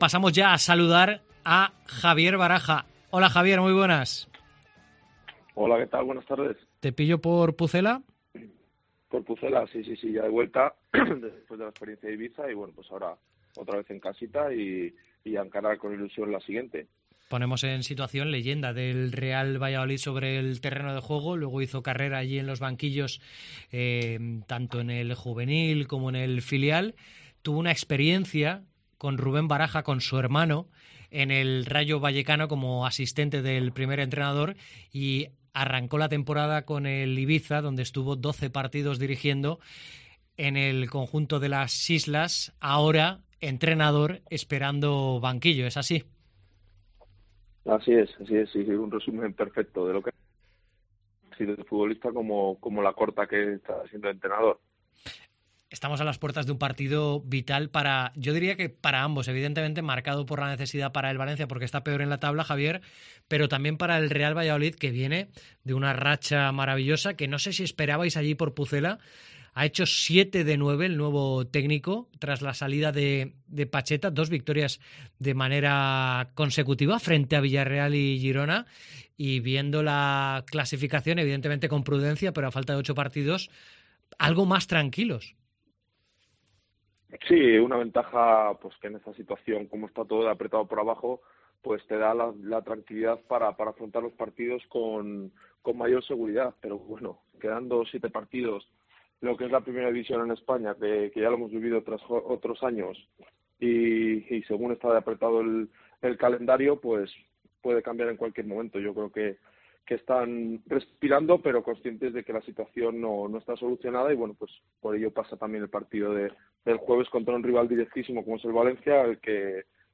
Hoy, Javier Baraja (42), leyenda del Valladolid , ha pasado por los micrófonos de Deportes COPE Valencia para hablar de su hermano antes de medirse al Pucela de su corazón.